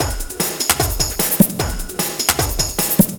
TEKKNO LOOP 2.wav